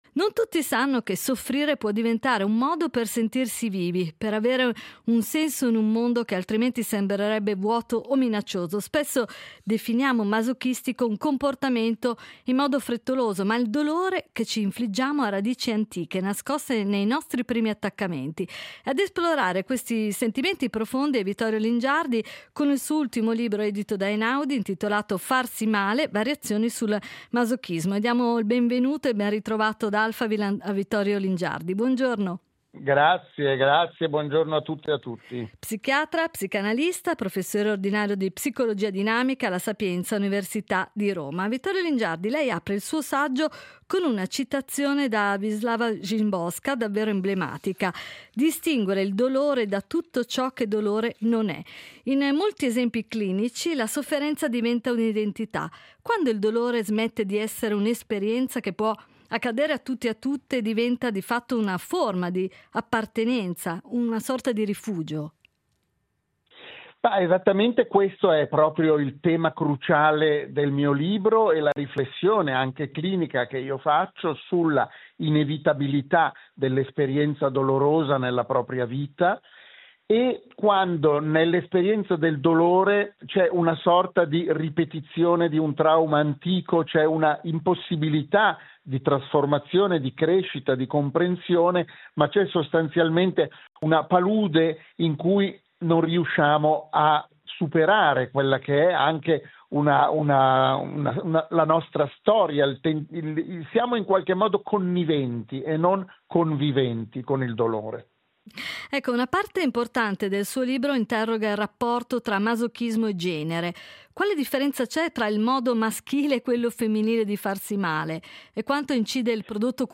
Un dialogo sulla “competenza amorosa” e sulla possibilità di trasformare, finalmente, il passato in un futuro immaginabile.